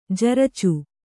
♪ jaracu